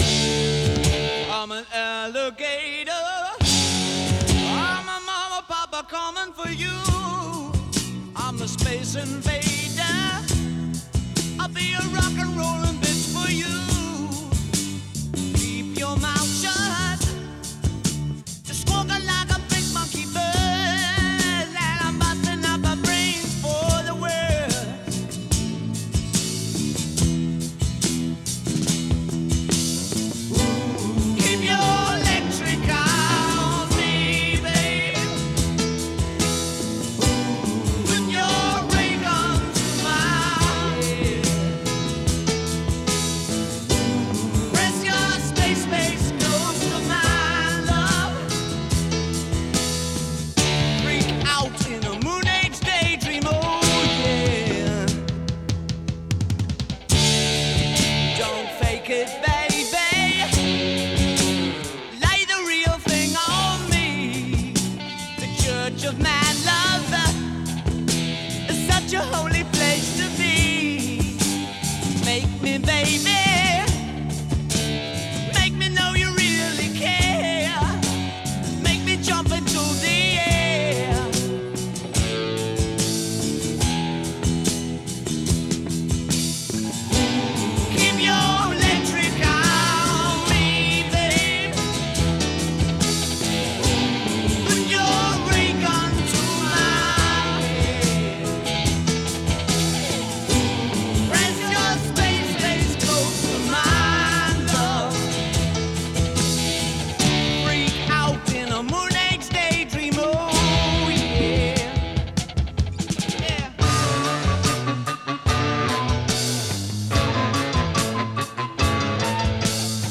Жанр: Art Rock, Glam-Rock